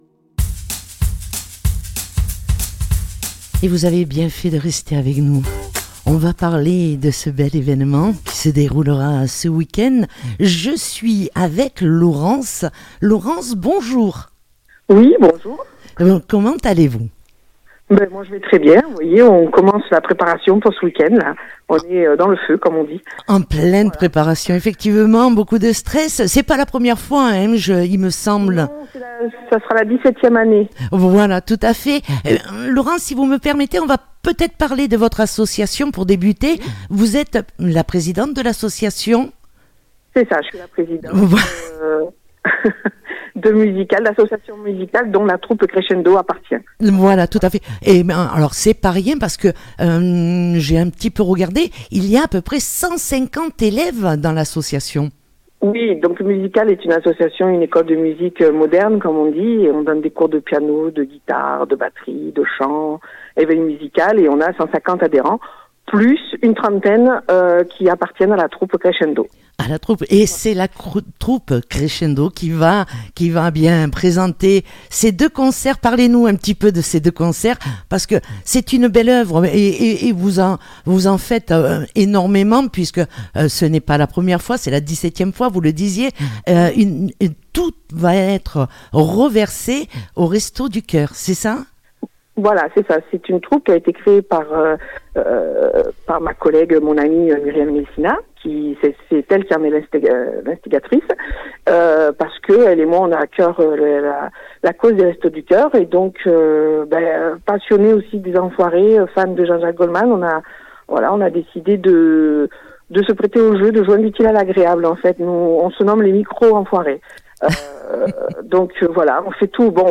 3. Interviews sur Radio Nîmes